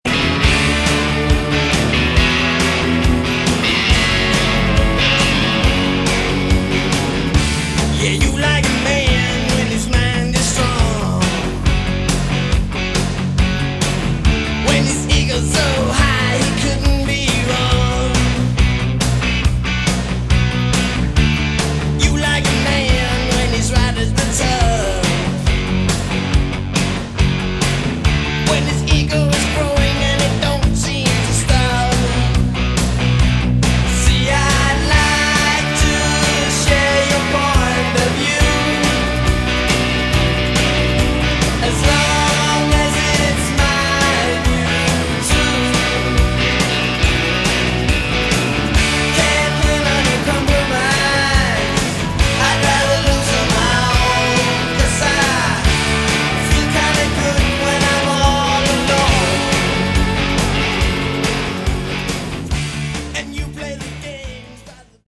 Category: Hard Rock
lead vocals, rhythm guitar
lead guitar, backing vocals
bass, backing vocals